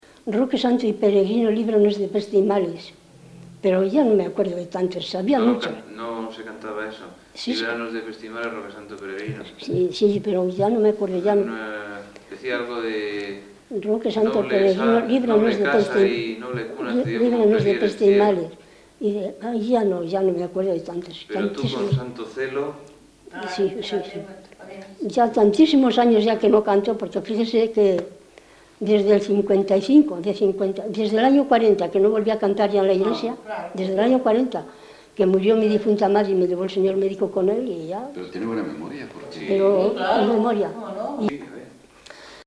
Grabación realizada en Palencia, en 1979.
Género / forma: Canciones populares-Palencia (Provincia) Icono con lupa